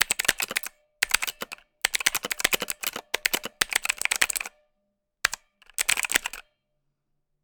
Keyboard, computer, mechanical, typing, quickly, rapidly, keys, press, button, click, tap_96Khz_Mono_ZoomH4n_NT5-003
button click computer key keyboard keypress press sound effect free sound royalty free Sound Effects